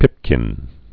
(pĭpkĭn)